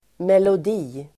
Uttal: [melod'i:]